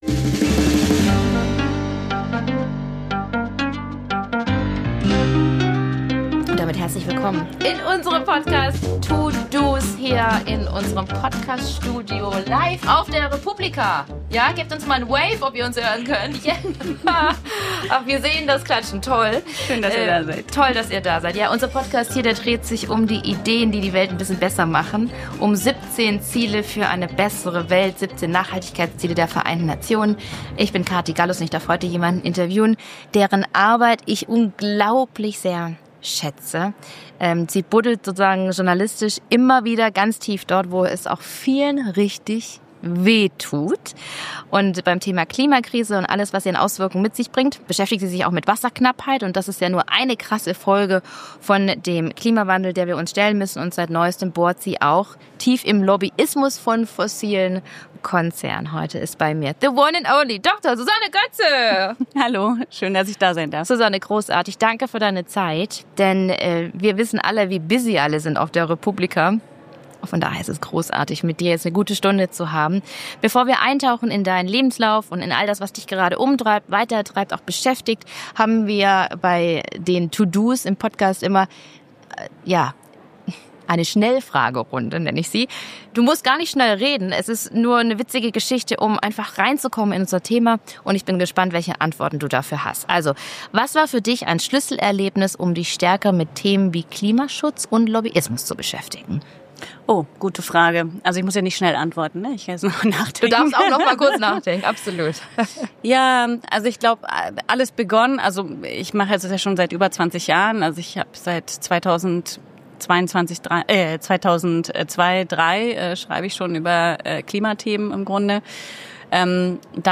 Ein Gespräch, das tiefer geht – unbequem, aber notwendig.